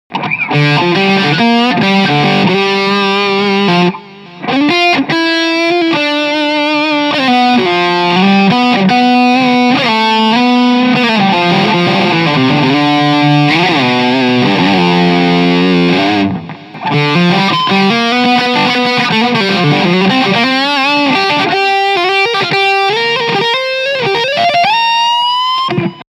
Lead Overdrive
Die heißen Humbucker und der geleimte Hals generieren einen durchsetzungsfähigen Klang mit gutem Sustain.
• Orange Tiny Terror, clean
• Shure SM57
hagstrom_fantomen_test__lead_be_od.mp3